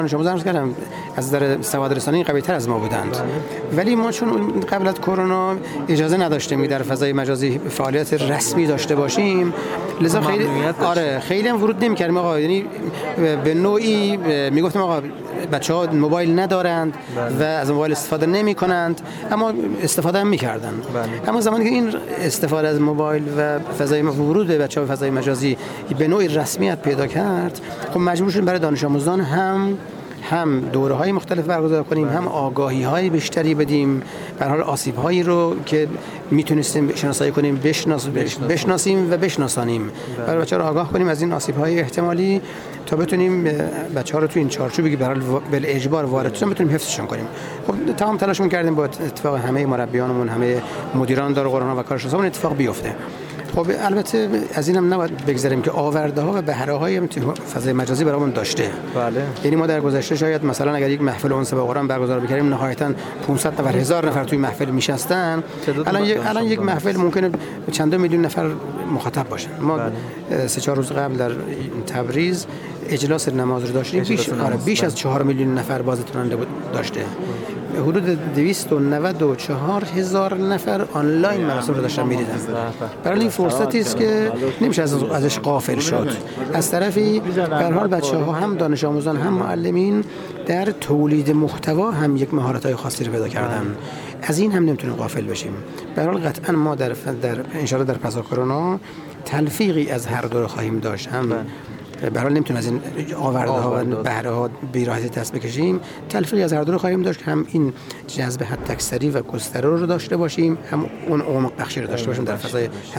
در همین راستا میکائیل باقری، مدیرکل قرآن، عترت و نماز وزارت آموزش‌ و پرورش در گفت‌و‌گو با ایکنا به تبیین دغدغه تربیت اسلامی پرداخت و اظهار کرد: با تعطیلی مدارس کشور به علت شیوع ویروس کرونا وزارت آموزش‌وپرورش، تعلیم و تربیت را تعطیل نکرد و از طریق شبکه‌های مجازی مختلف و رسانه ملی پیگیری لازم برای جبران این تعطیلی‌ها را سامان‌دهی کرد؛ اما وجود شبکه‌های مجازی گوناگون و پیام‌رسان‌های مختلفی که خانواده‌ها باید برای استفاده دانش‌آموزان استفاده می‌کرد عزم مسئولان آموزش‌وپرورش را جزم کرد تا برای ساماندهی و استفاده از یک شبکه واحد آموزشی با عنوان شبکه شاد یا همان شبکه اجتماعی دانش‌آموزان اقدام کند.